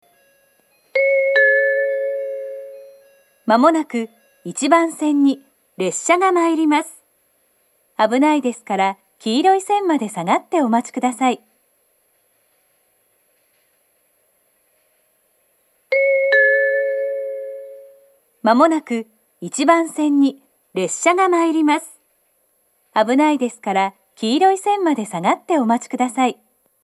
接近放送の流れるタイミングは早くなく、接近表示機が点滅してから録音をはじめても十分に間に合います。
その後すぐに設定を変更したようで、２０１９年３月下旬には上下で放送の男女が入れ替わり、言い回しも変更されています。
１番線接近放送 上り本線です。
minami-sendai-1bannsenn-sekkinn1.mp3